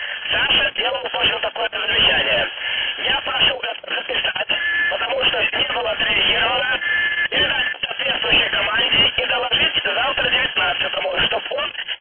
I have some very old audio files with Soviet cosmonauts talking in inverted spectrum voice.
I don’t speak Russian so I don’t know if this is intelligible or not.
It sounds pretty good.